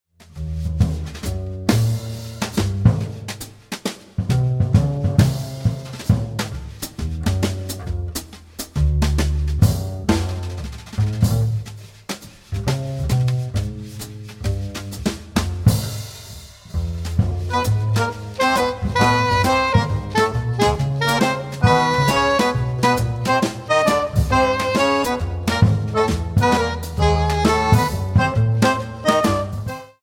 soprano sax, bassclarinet
accordion
double bass
drums